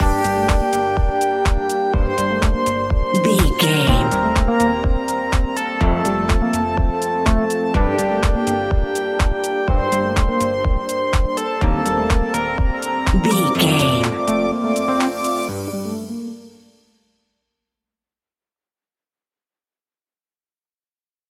Aeolian/Minor
groovy
synthesiser
drum machine
electric piano
funky house
deep house
nu disco
upbeat
funky guitar
synth bass